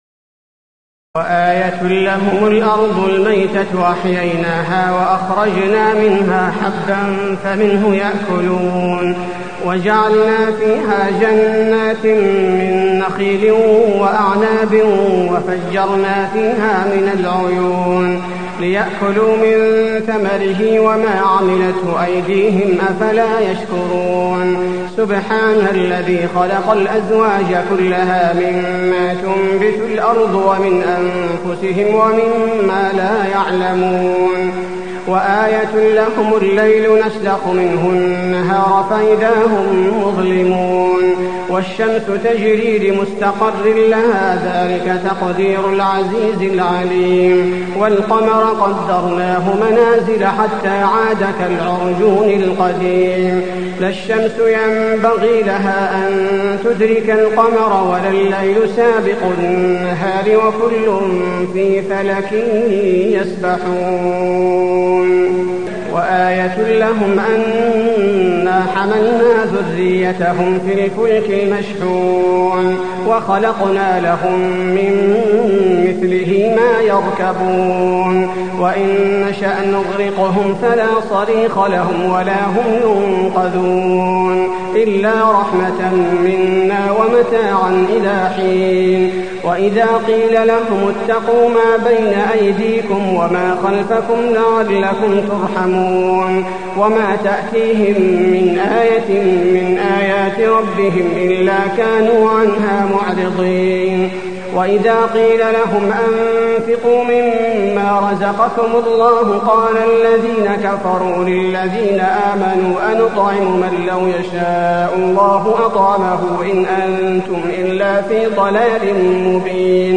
تراويح ليلة 22 رمضان 1424هـ من سور يس (33-83) والصافات (1-138) Taraweeh 22 st night Ramadan 1424H from Surah Yaseen and As-Saaffaat > تراويح الحرم النبوي عام 1424 🕌 > التراويح - تلاوات الحرمين